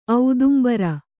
♪ audumbara